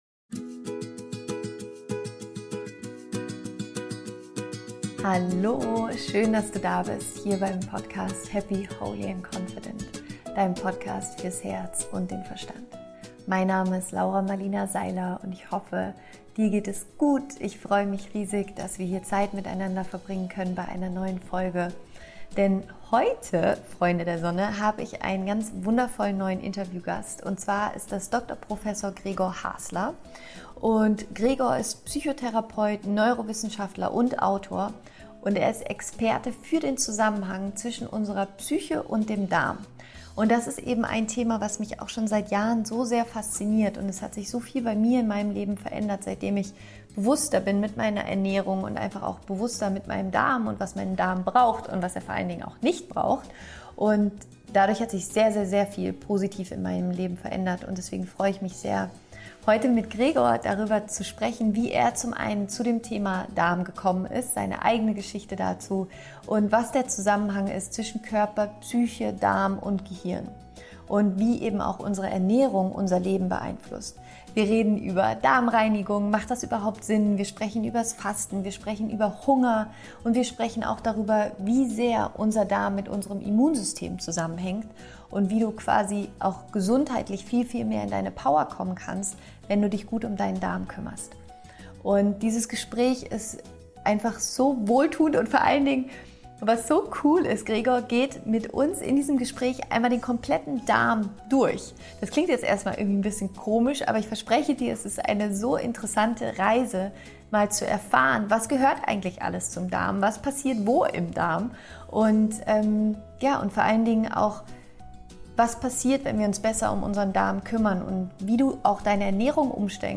inspirierenden Menschen im Podcastinterview